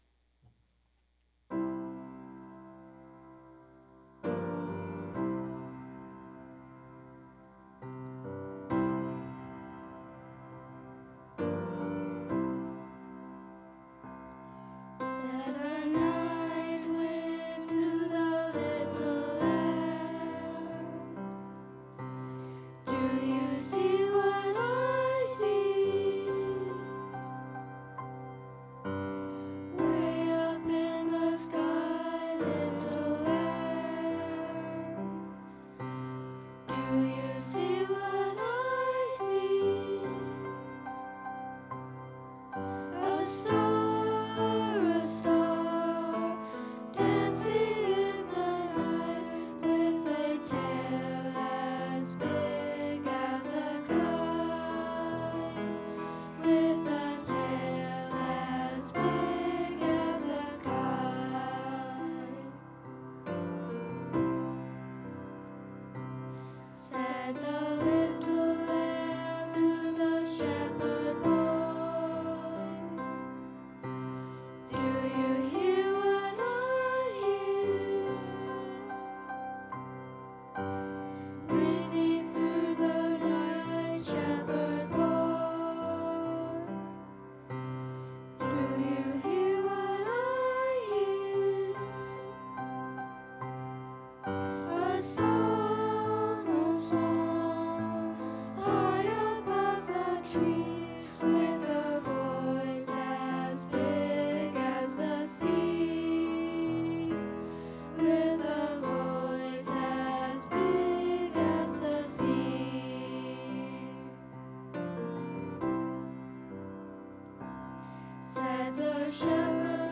2022 Christmas Program Practice Tracks